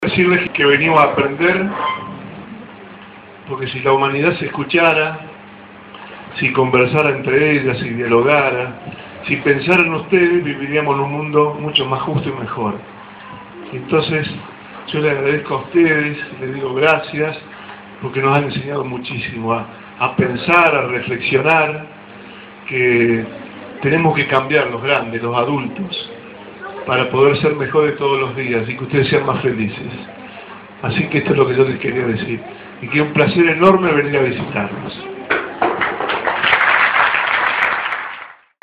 Bonfatti estuvo presente en el cierre de la Asamblea de Niñas y Niños en San Justo, donde los más pequeños expresaron lo que esperan y necesitan de los adultos.